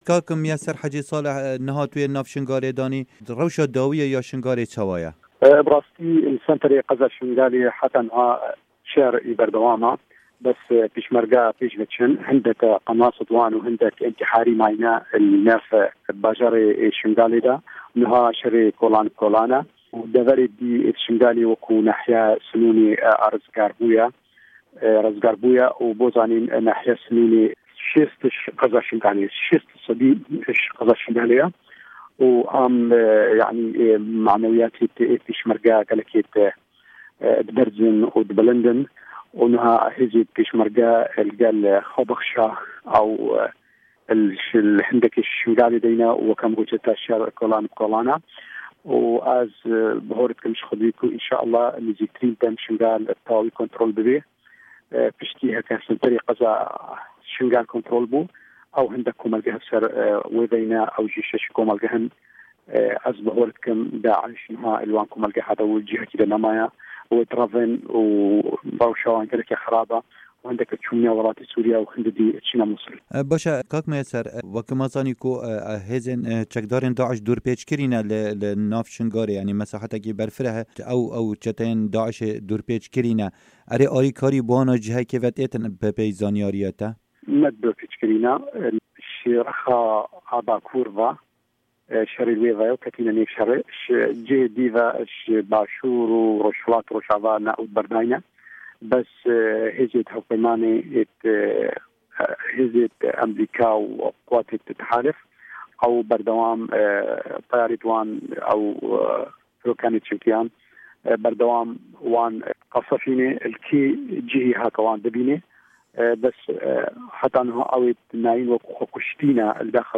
hevpeyvin digel Mûyeser Haci Salih